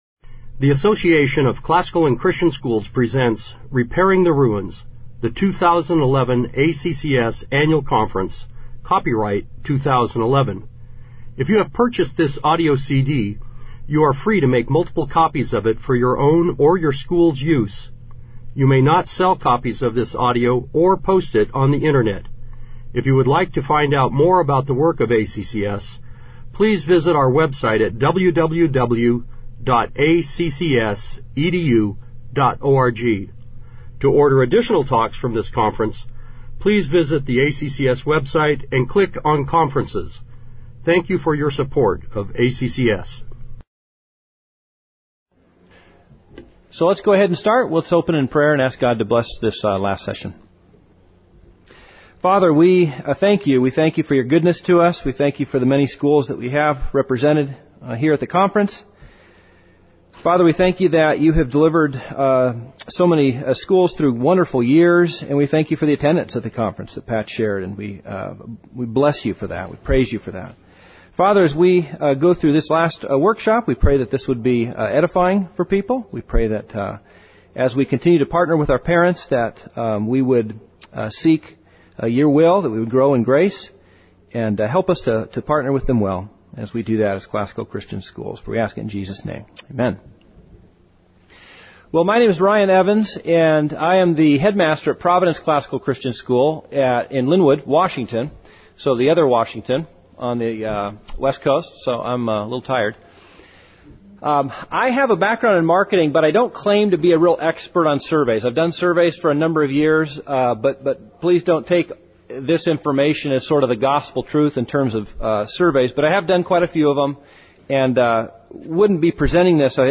2011 Workshop Talk | 1:01:24 | All Grade Levels, Leadership & Strategic
The Association of Classical & Christian Schools presents Repairing the Ruins, the ACCS annual conference, copyright ACCS.